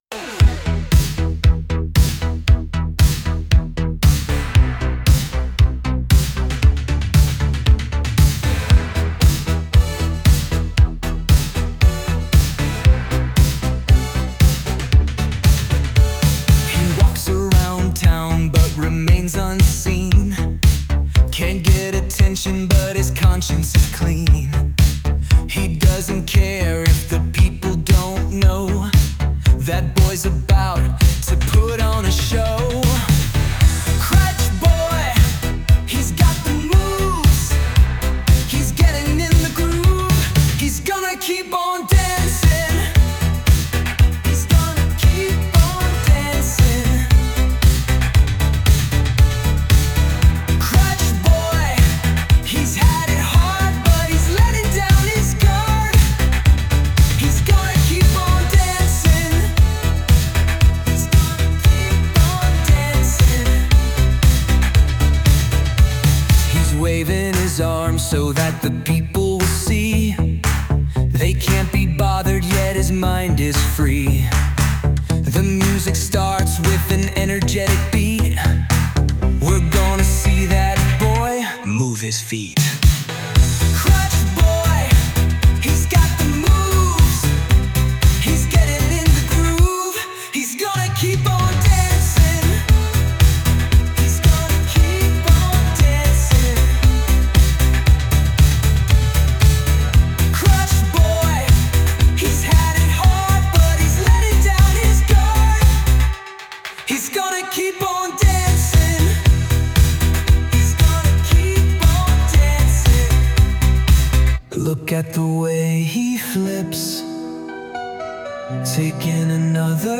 This one really captured the ’80s feel I was going for…